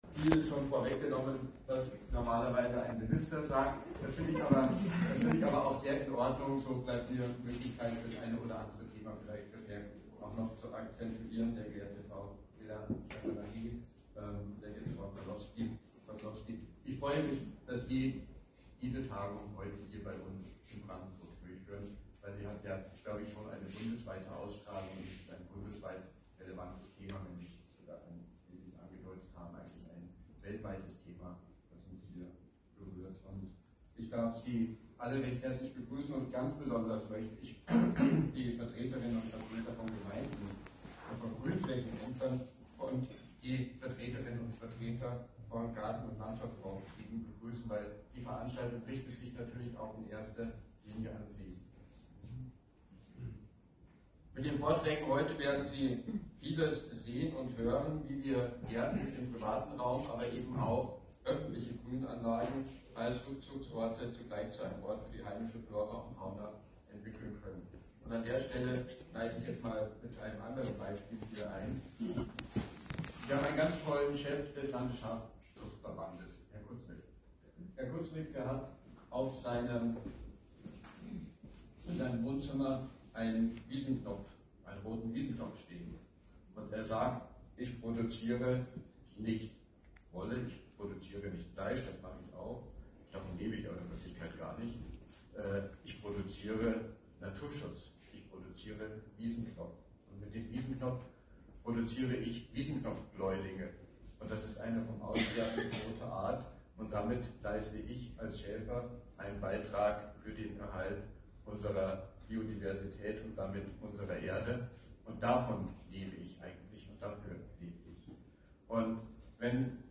brandenburgs-umweltminister-axel-vogel_grussworte.m4a